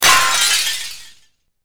breakingglass3.wav